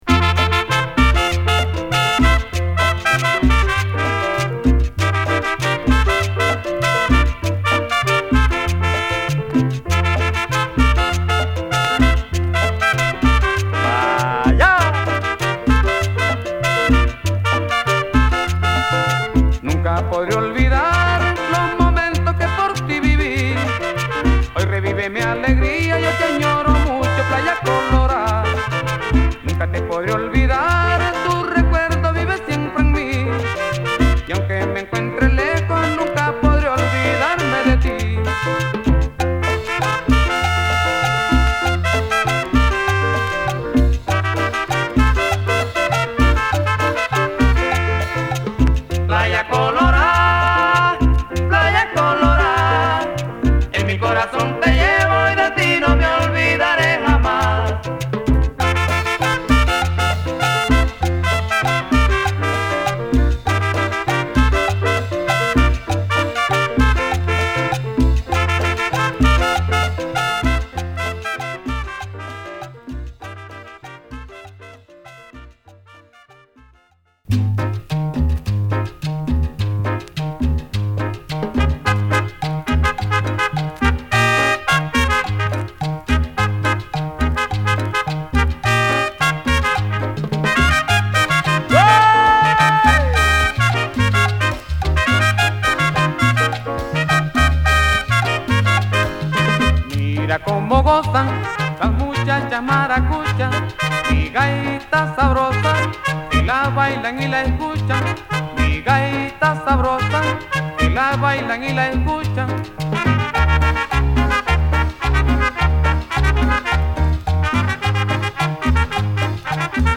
ジャケットの笑顔を裏切らない爽やかなヴォーカルを聴かせるナイスクンビアを満載！